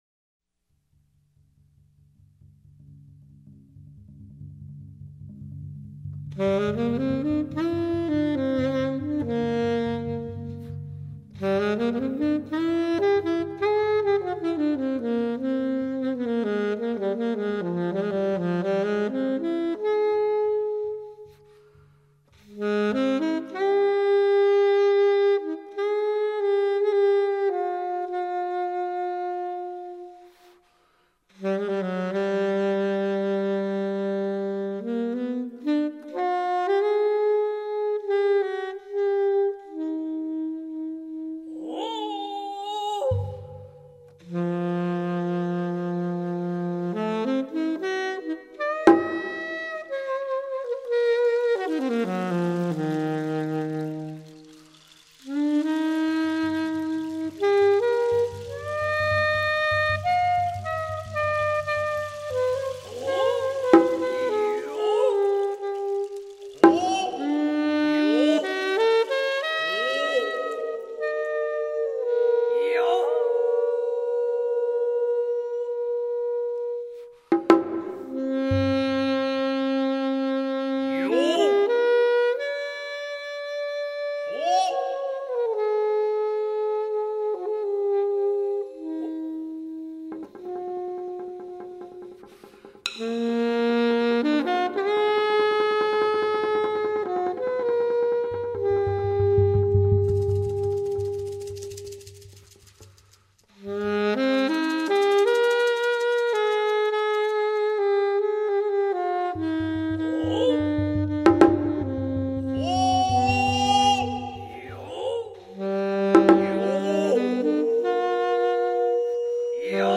taiko, percussion, alto saxophone, fue, Mexican clay flute